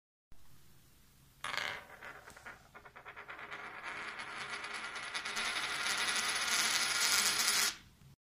bozuk-para-sesicoin-sound.mp3